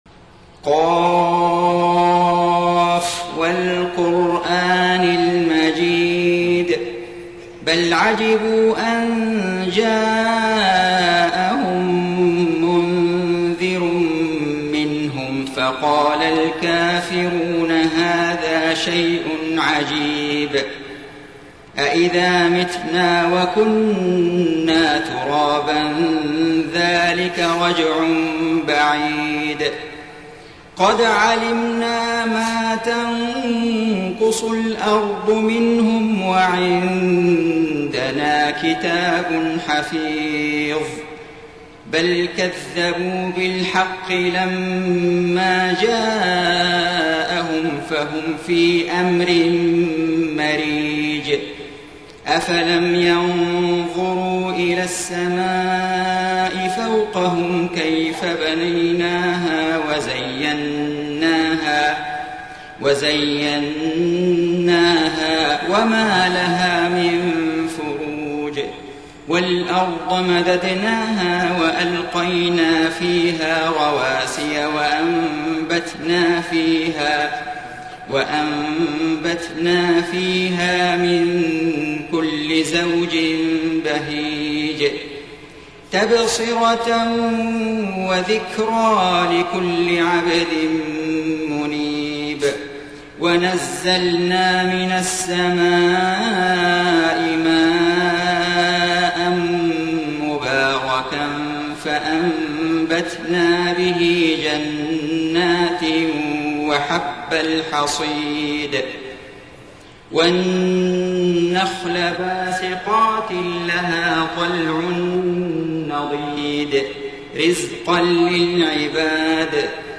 سورة ق > السور المكتملة للشيخ فيصل غزاوي من الحرم المكي 🕋 > السور المكتملة 🕋 > المزيد - تلاوات الحرمين